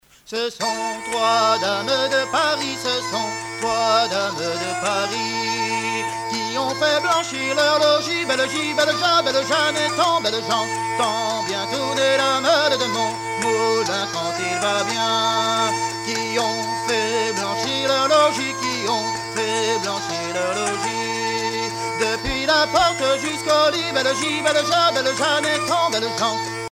danse : ronde : grand'danse
Genre laisse
Pièce musicale éditée